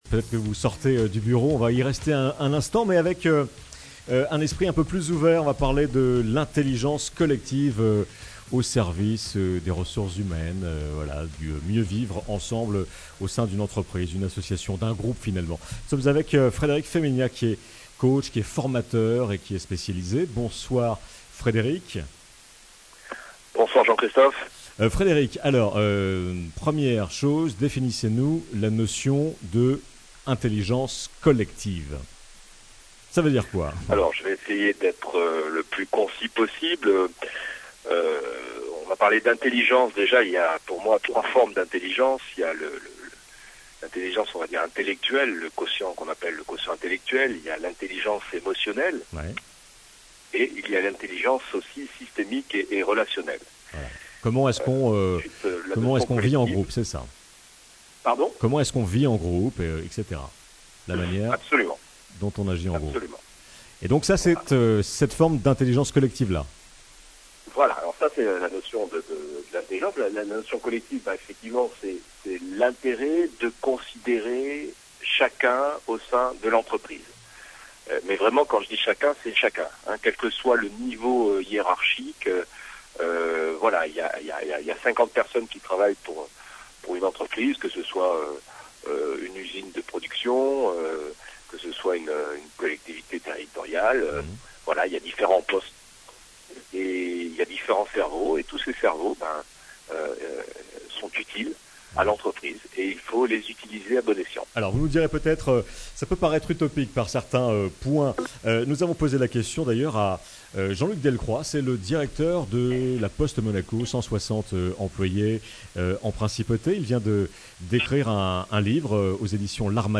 Interview sur Monaco Radio